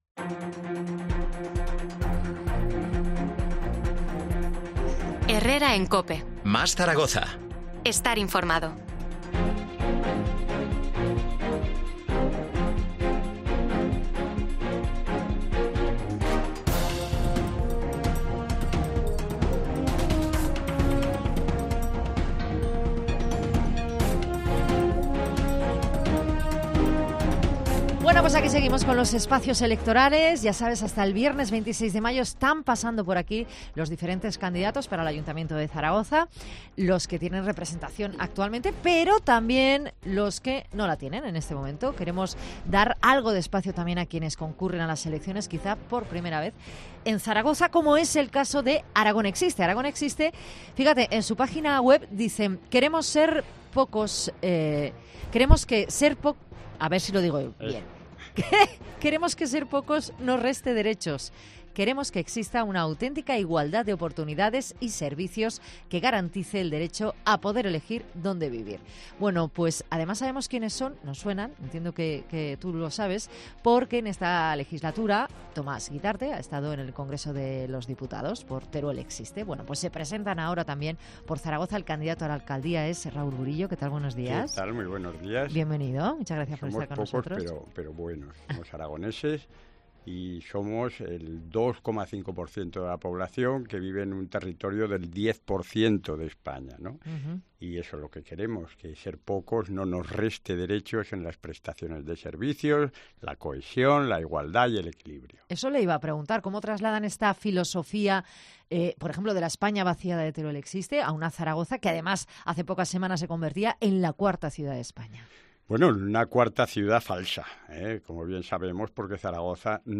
Entrevista
Este martes, desde nuestros estudios en COPE Zaragoza